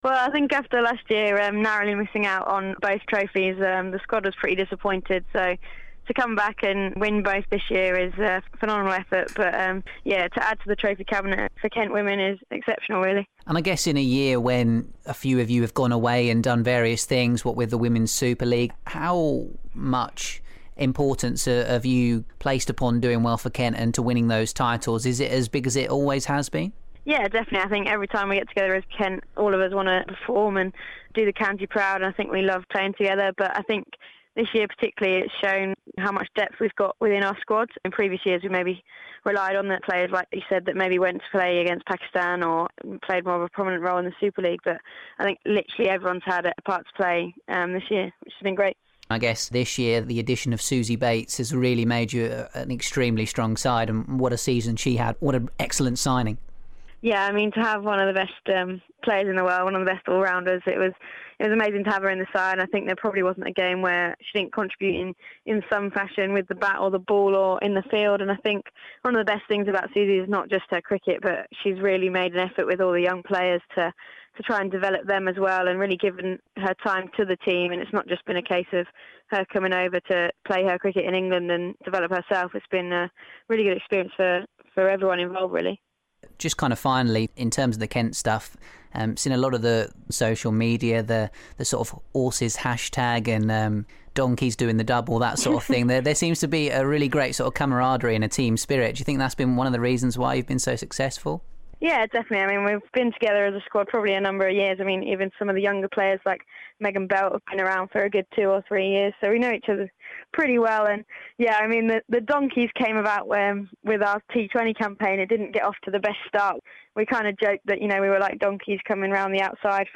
caught up with England international Tammy Beaumont to talk trophies, hundreds & the Women's Super League.